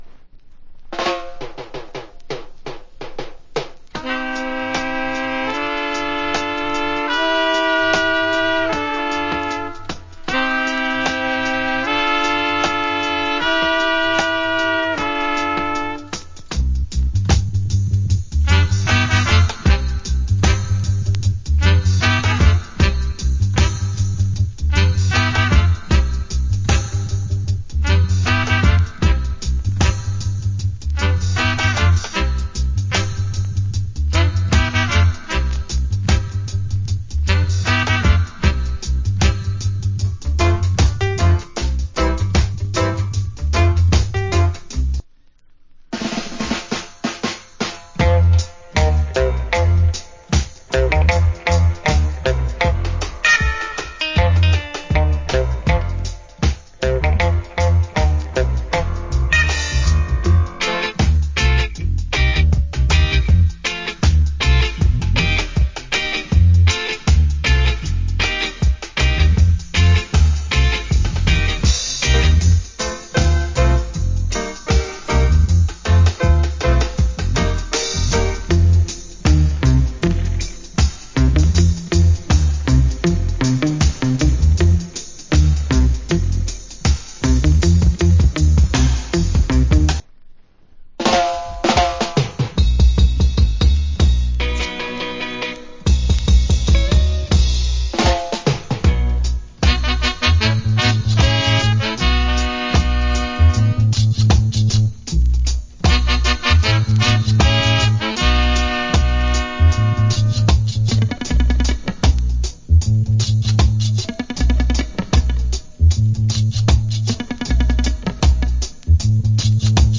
Great Dub